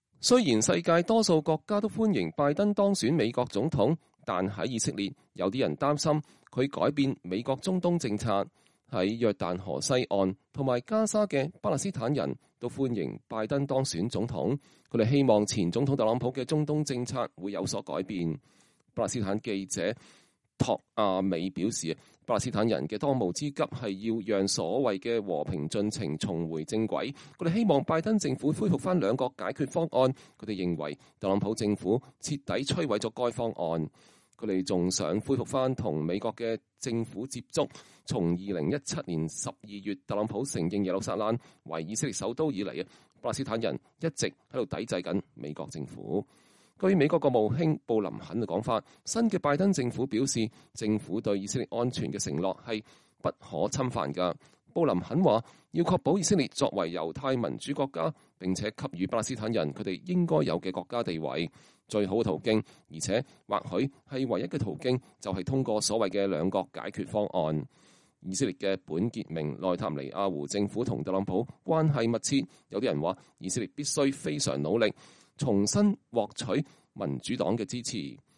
耶路撒冷 —